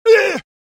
人类 " 男性战斗03
描述：这个战斗尖叫声是为一个mmorpg电脑游戏录制的
Tag: 疼痛 尖叫